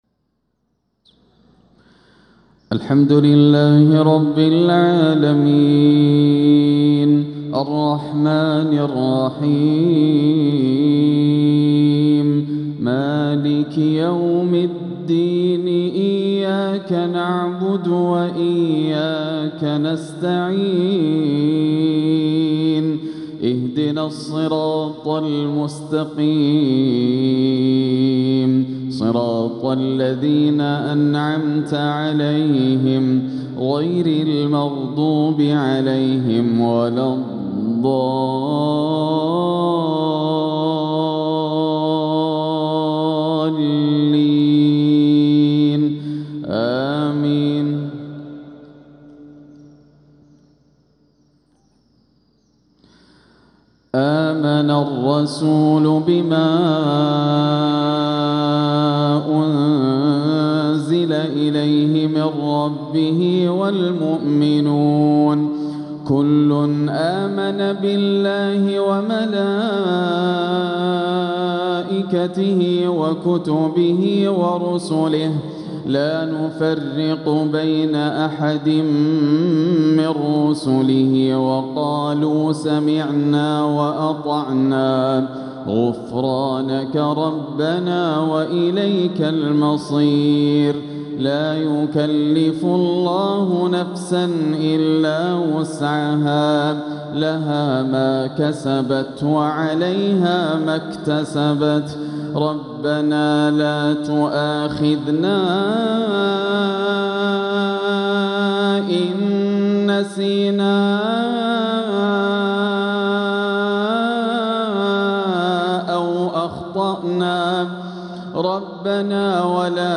تلاوة راائعة لآخر سورة البقرة والإخلاص | مغرب الأربعاء 11 شوال 1446 > عام 1446 > الفروض - تلاوات ياسر الدوسري